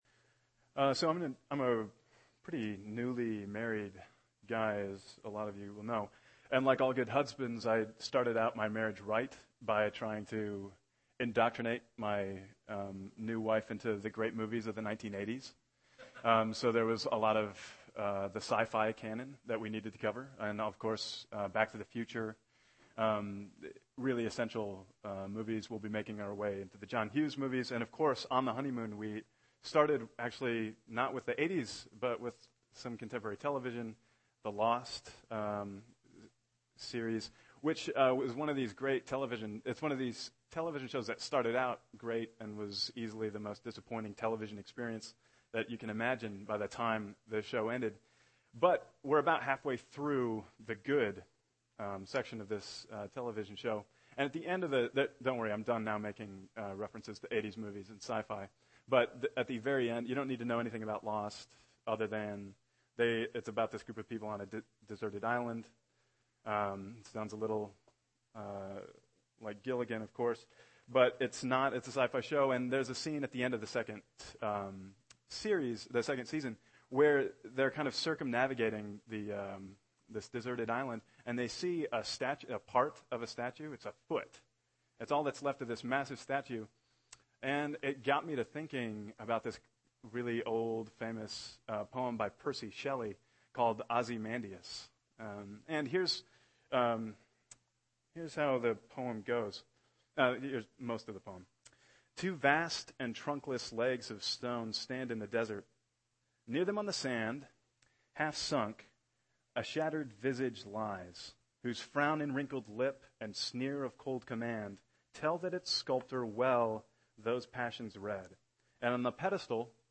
August 12, 2012 (Sunday Morning)